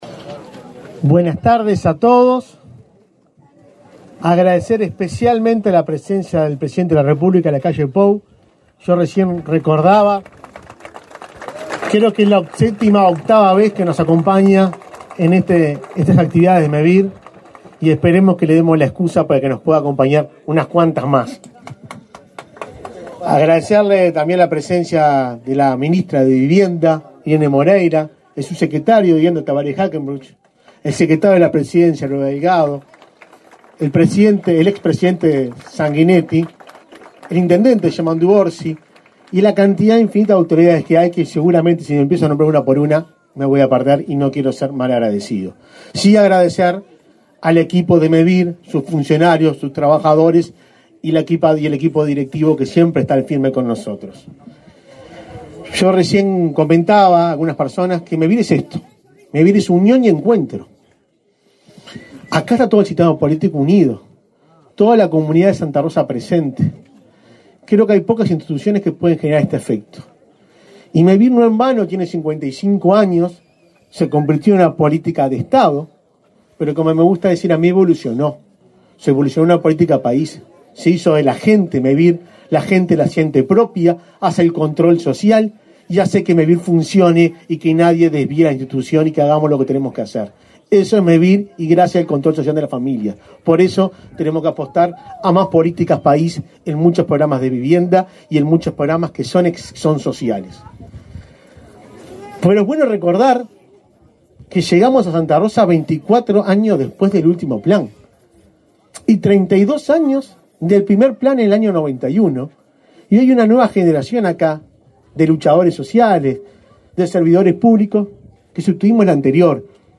Conferencia de prensa por inauguración de viviendas en Santa Rosa
Conferencia de prensa por inauguración de viviendas en Santa Rosa 28/03/2023 Compartir Facebook X Copiar enlace WhatsApp LinkedIn Con la presencia del presidente de la República, Luis Lacalle Pou, Mevir inauguró 44 viviendas en la localidad de Santa Rosa, en Canelones, este 28 de marzo. Disertaron en el evento el presidente de Mevir, Juan Pablo Delgado, y la ministra de Vivienda y Ordenamiento Territorial, Irene Moreira.